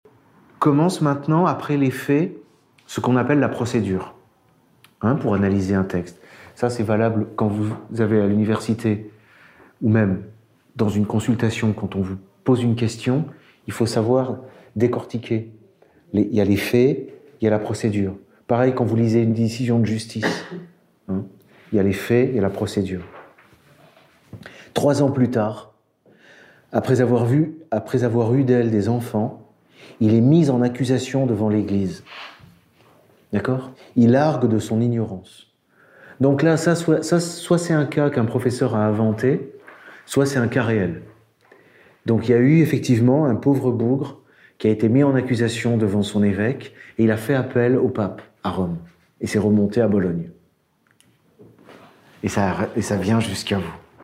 Il s’agit de la 2e des douze heures du cours de l’année 2018-2019 qui portait sur le droit civil.